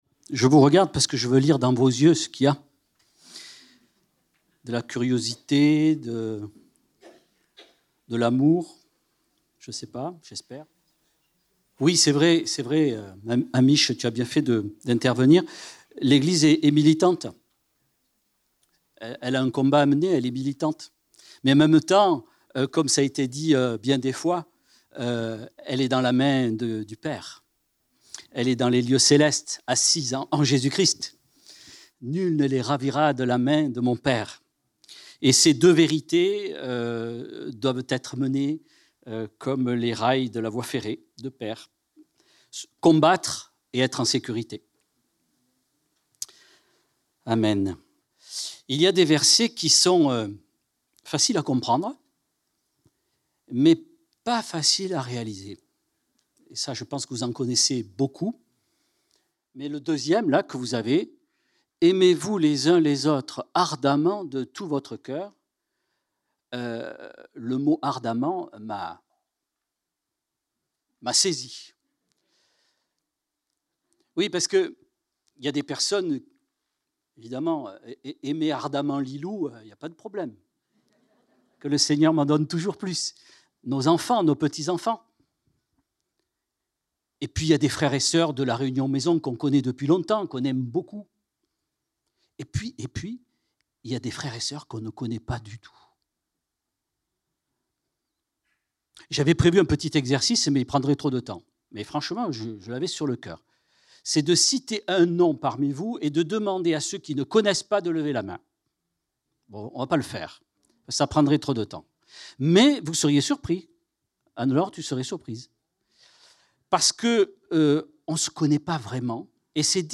prédication